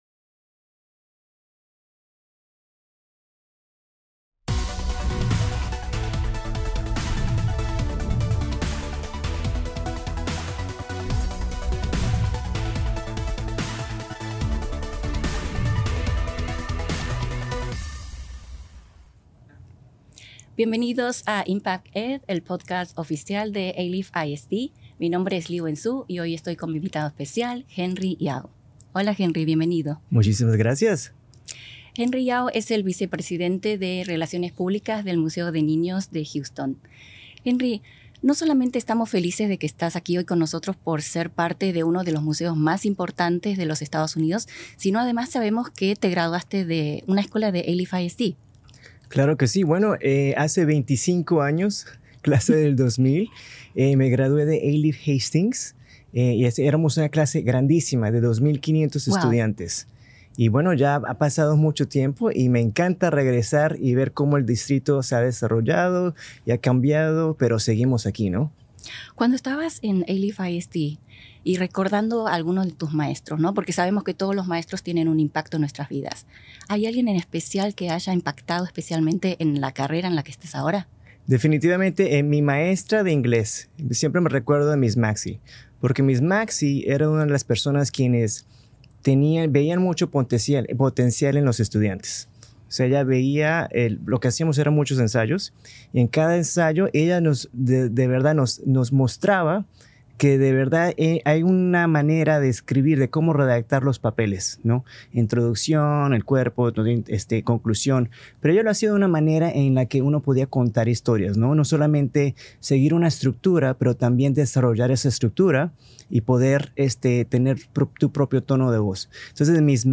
This insightful conversation highlights the power of community, education, and the lasting impact of Alief ISD on its alumni.